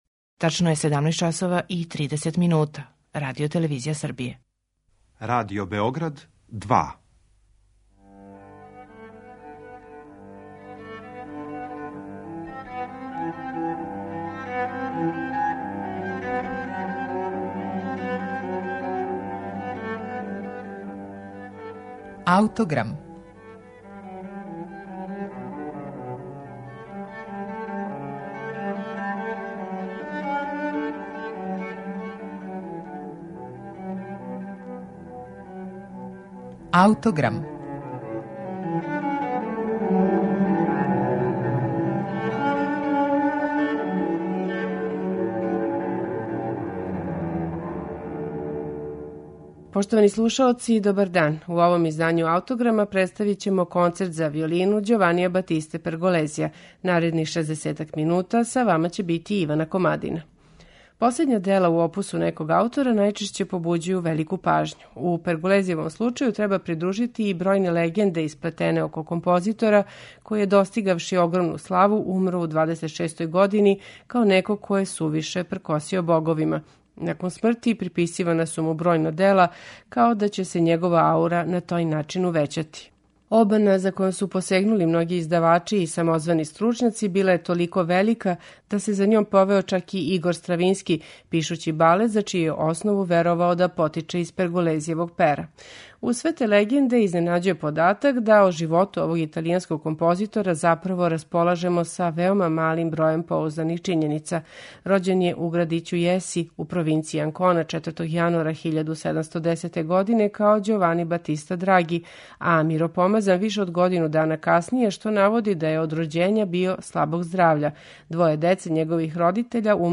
Представићемо Концерт за виолину и оркестар у Бе-дуру Ђованија Батисте Перголезија
У данашњем Аутограм, Концерт за виолину и оркестар у Бe-дуру Ђованија Батисте Перголезија слушаћемо на концертном снимку Ђулијана Кармињоле, оркестра Моцарт и диригента Клаудија Абада.